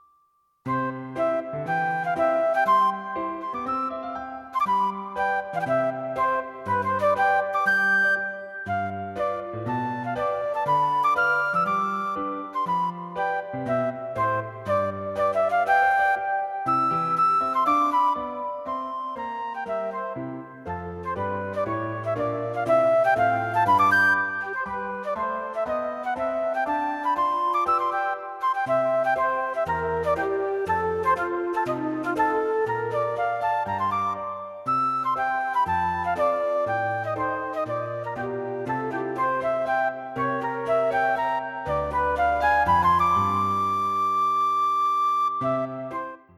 Flute and Piano